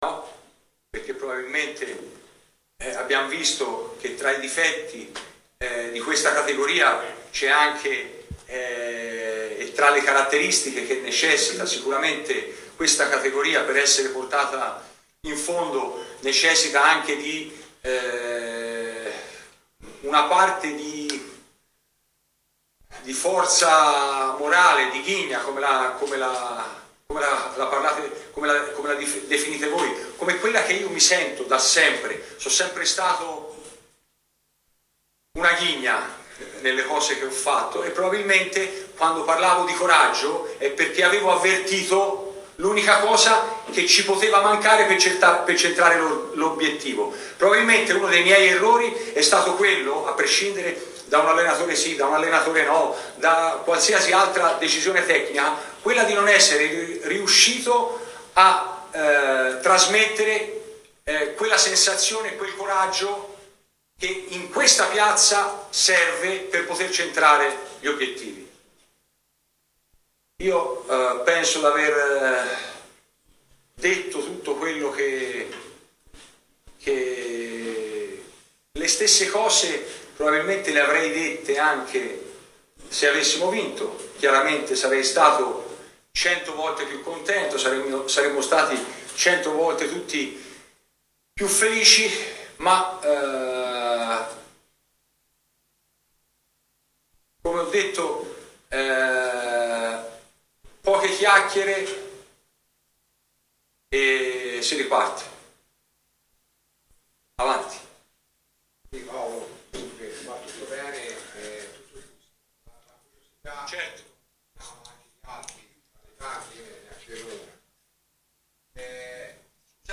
Una conferenza stampa molto affollata questo pomeriggio di cui potete ascoltare una parte delle dichiarazioni del presidente stesso.